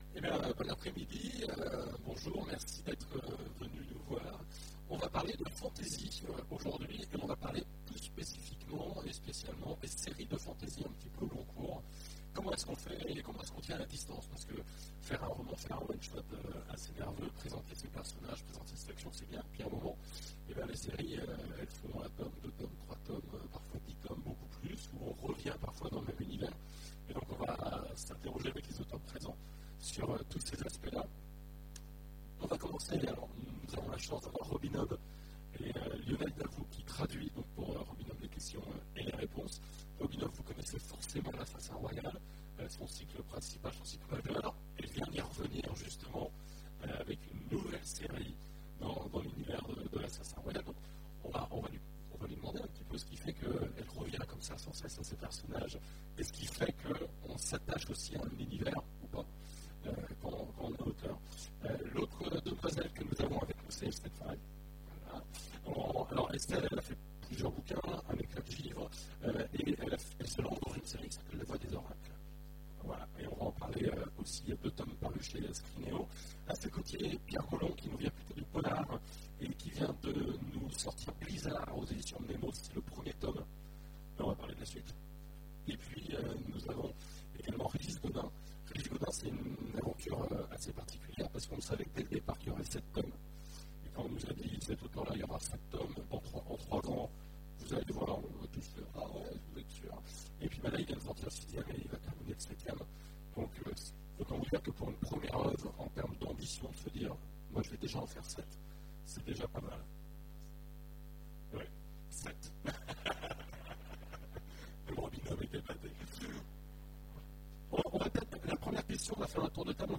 Imaginales 2015 : Conférence Bâtir une série de fantasy